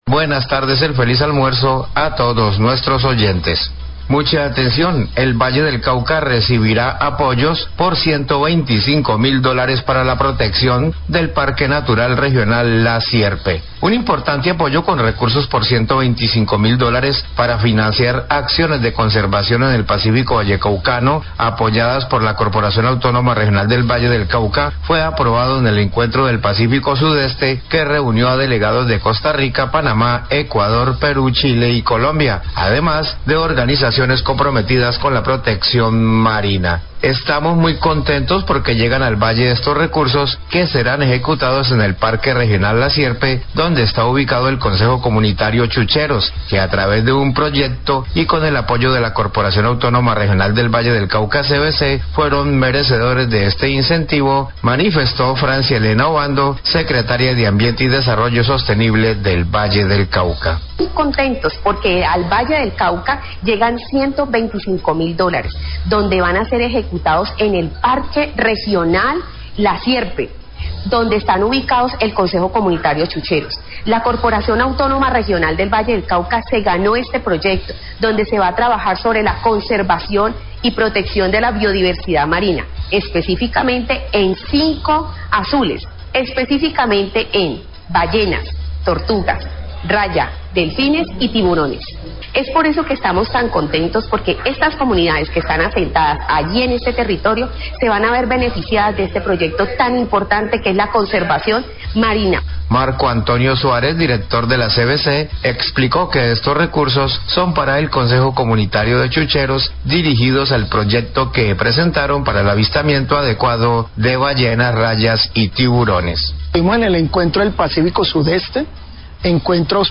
Radio
El Director de la CVC, Marco Antorio Suarez, habla al respecto.